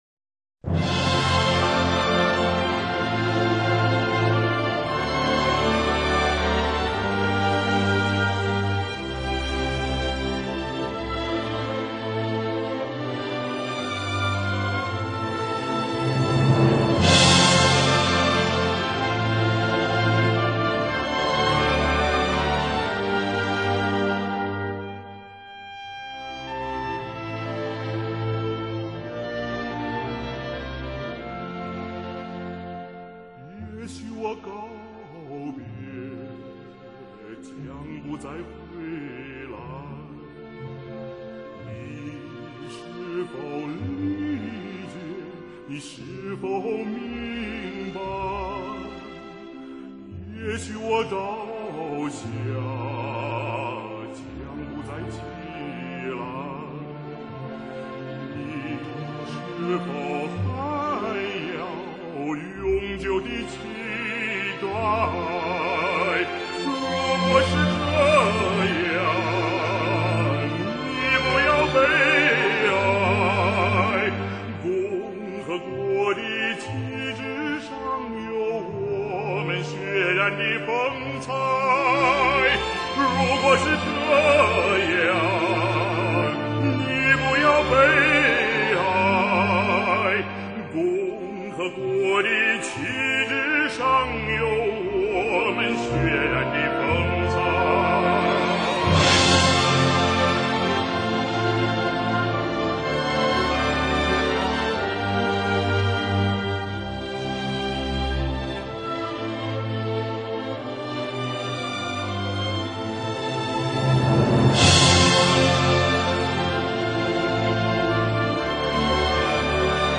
3支歌曲连播
1-3 合成音轨，音源来自互联网分享，音质不够理想，见谅